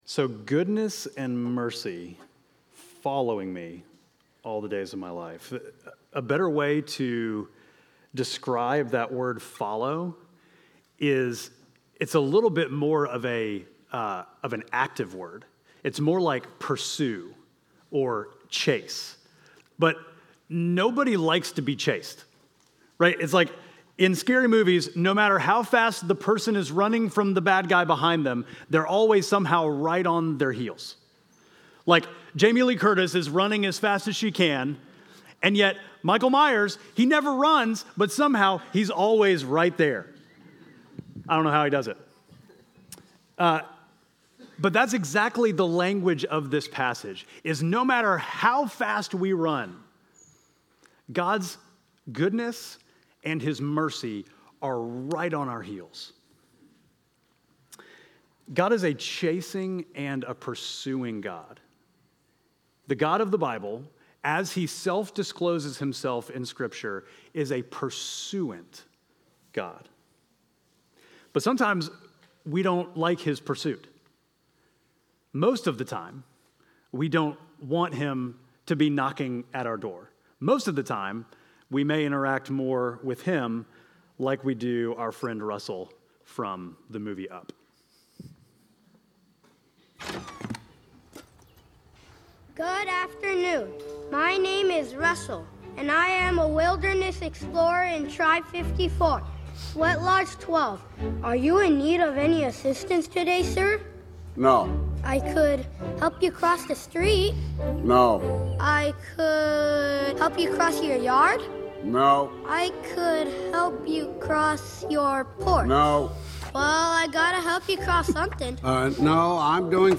Midtown Fellowship Crieve Hall Sermons The Chase Aug 04 2024 | 00:26:14 1x 00:00 / 00:26:14 Subscribe Share Apple Podcasts Spotify Overcast RSS Feed Share Link Embed